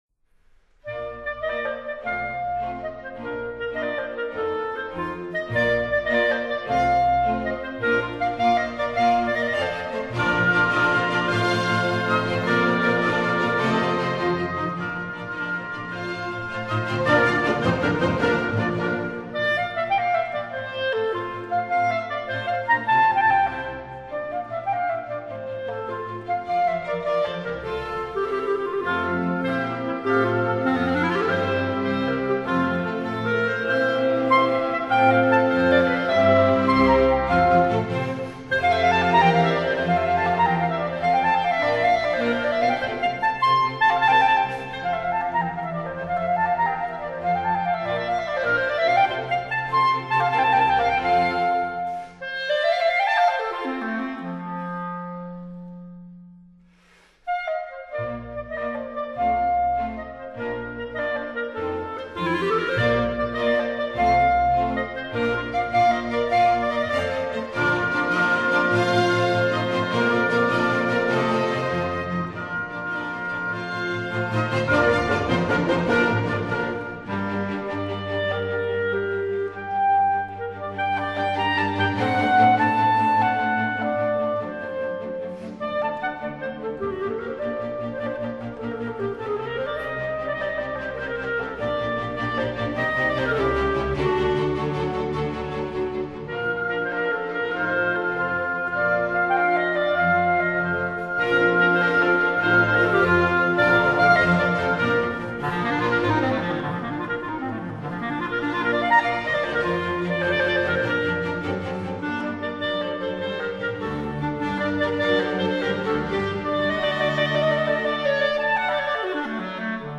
巴洛克音樂介紹